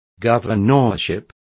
Complete with pronunciation of the translation of governorship.